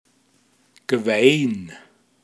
Pinzgauer Mundart Lexikon
Details zum Wort: gwe-in. Mundart Begriff für gewesen